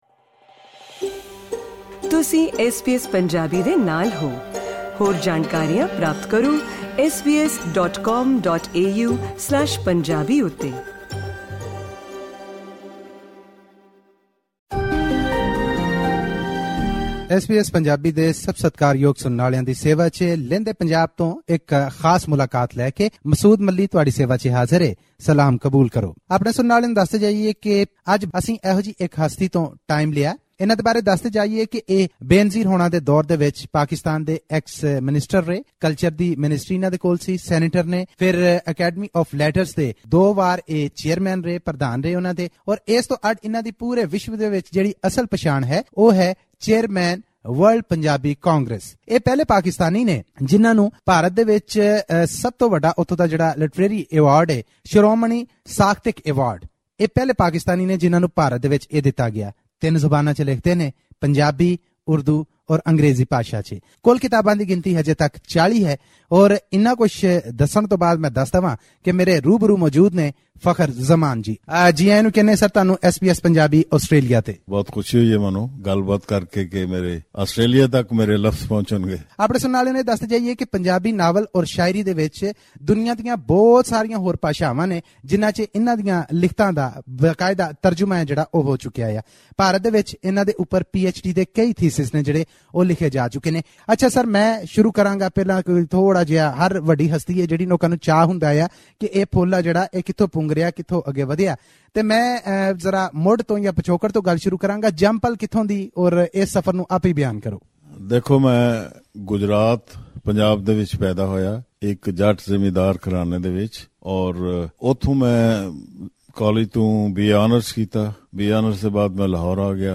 Interview with Punjabi poet and writer Fakhar Zamaan